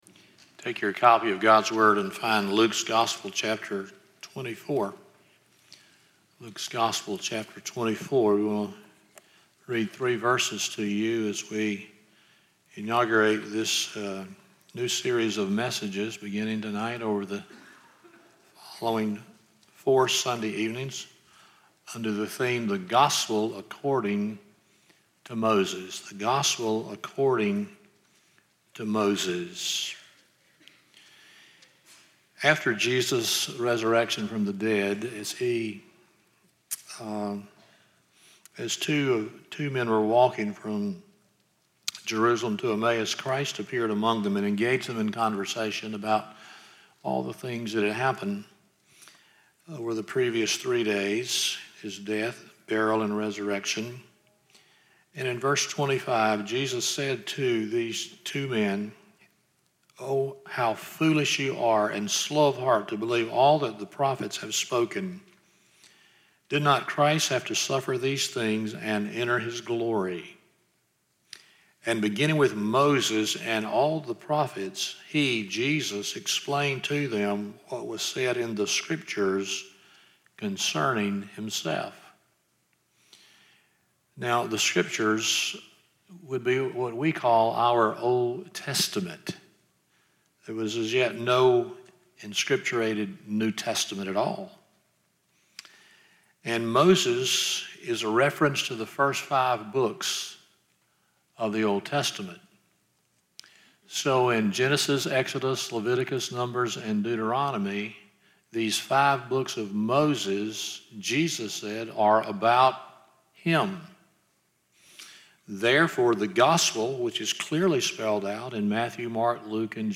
Luke 24:25-27 Service Type: Sunday Evening 1.